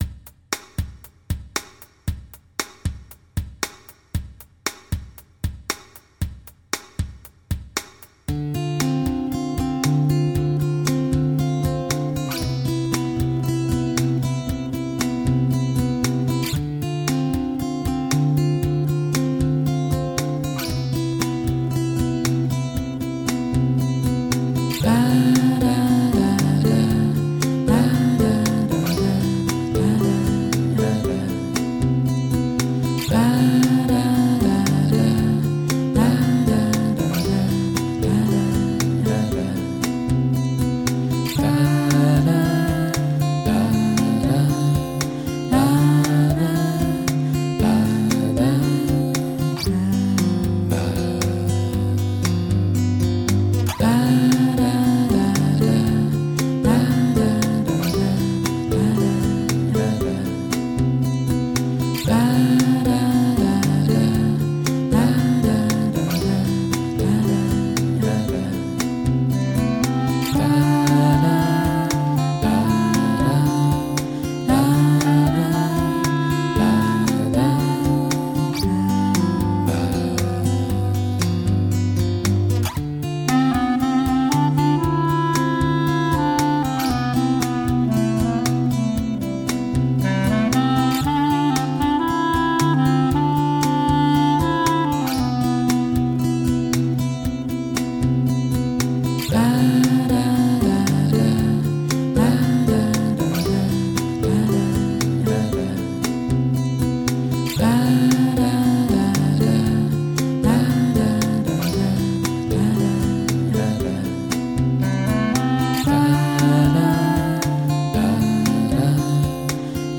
萌芽自花東田野的樂活心民謠　簡單唱　唱得你身心鬆爽